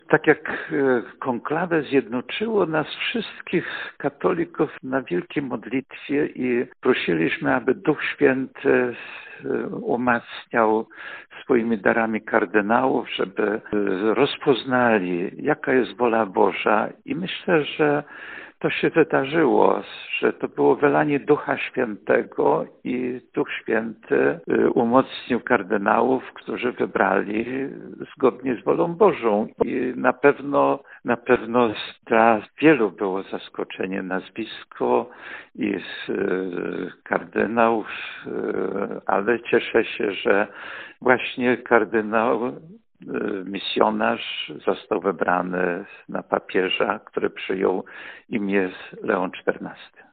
– Cieszę się z wyboru kardynała misjonarza na papieża – tak o decyzji purpuratów podczas konklawe – mówi biskup diecezji ełckiej ks. Jerzy Mazur.